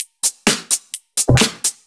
Index of /VEE/VEE2 Loops 128BPM
VEE2 Electro Loop 120.wav